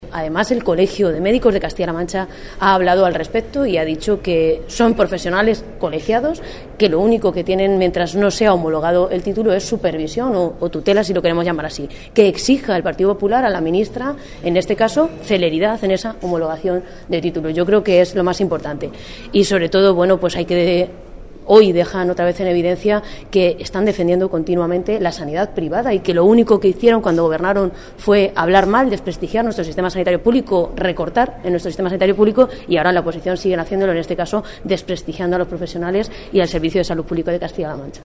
La diputada del Grupo socialista, Ana Isabel Abengózar, ha señalado hoy que tras el comunicado que han firmado 54 médicos del hospital de Puertollano en el que apoyan la labor de sus compañeros especialistas que tienen el título sin homologar, a los dirigentes del PP “solo les queda un camino que es el de pedir disculpas a estos profesionales y a las pacientes atendidas por los mismos y dejar de una vez de atacar y denigrar la sanidad pública de nuestra comunidad autónoma”.
Cortes de audio de la rueda de prensa